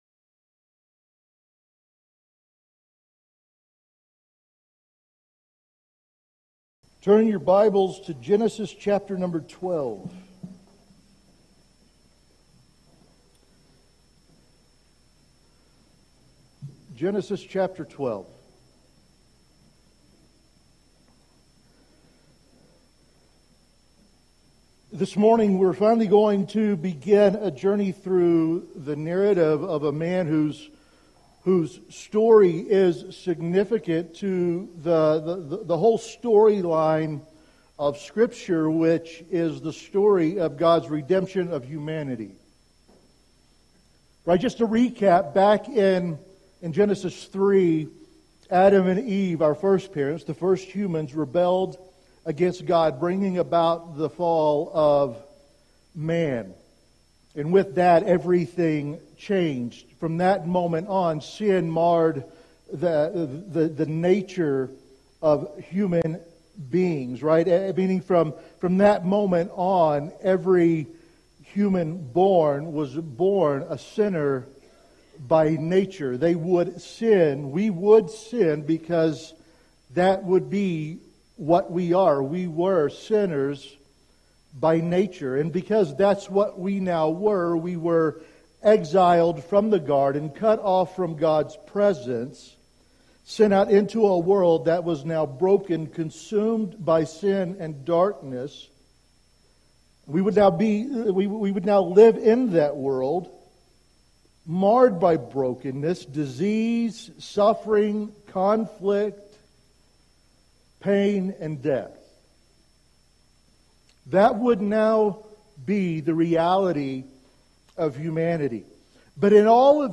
Sermons | Big Horn Baptist Church